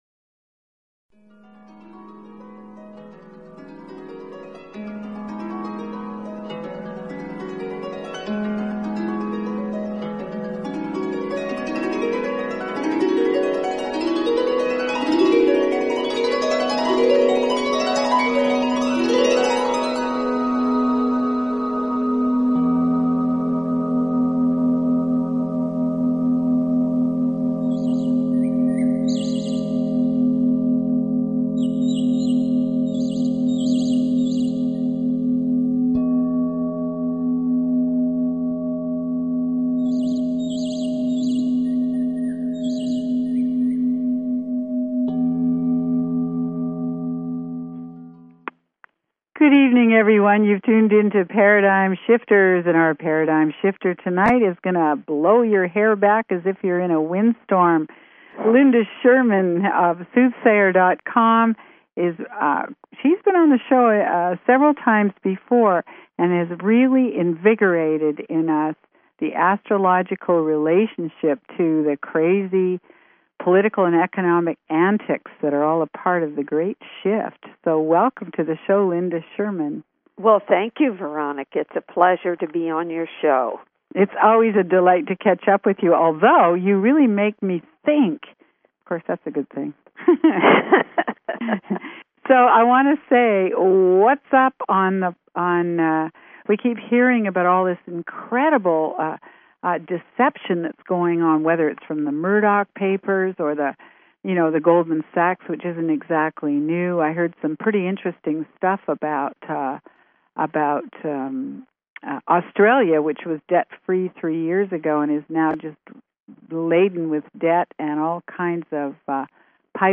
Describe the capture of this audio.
This is a CALL IN show so dig out your charts and call in with questions about your journey, some global perspectives, and your gifts for handling the SHIFTING times.